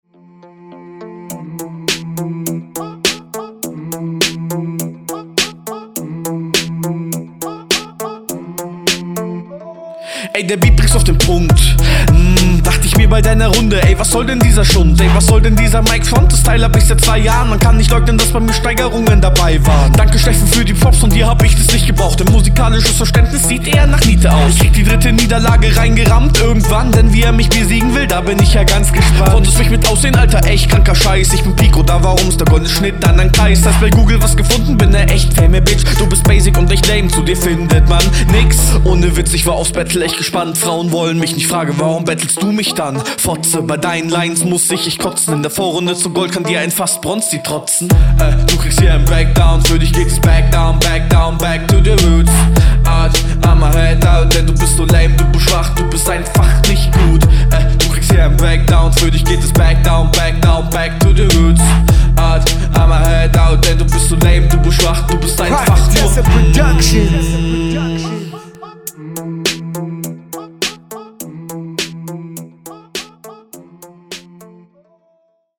Wesentlich weniger stylisch, als deine HR1, aber die Hook viel besser, als dein Gegner in …